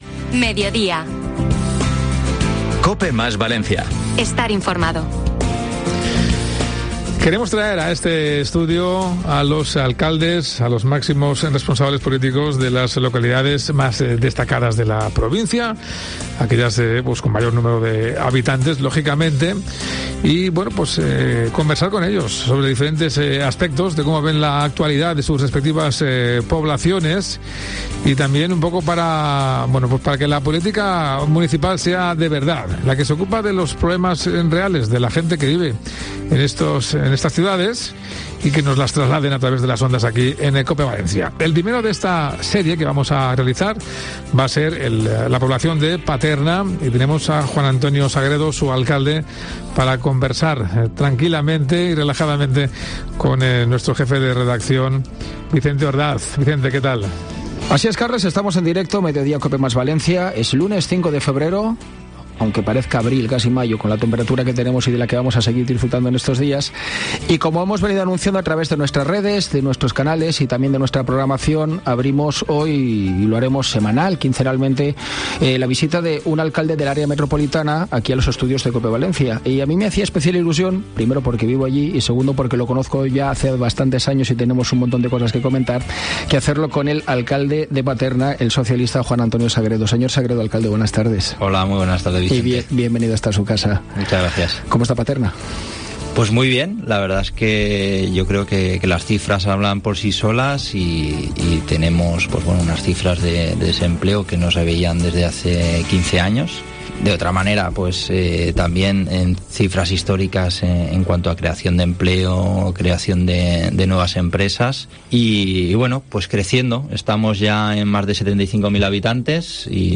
El alcalde de Paterna, Juan Antonio Sagrego, visita los micrófonos de COPE Valencia para hablar sobre el buen momento empresarial de la localidad